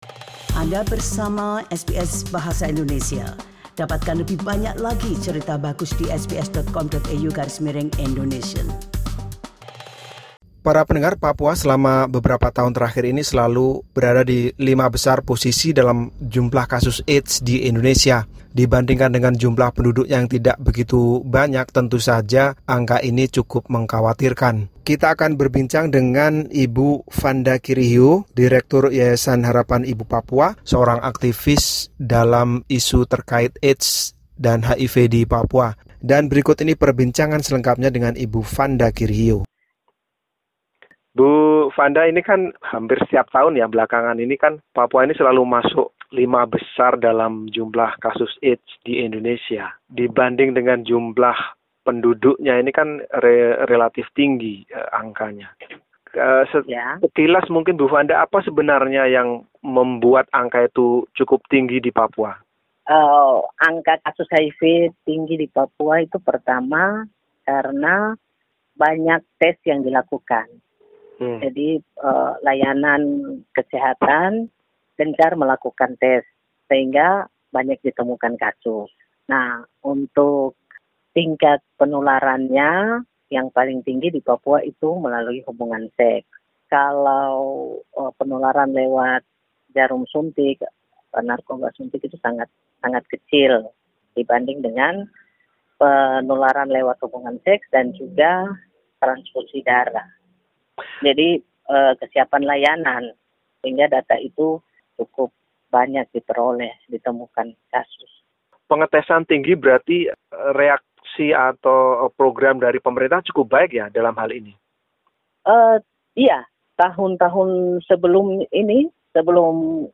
will explain in the following interview.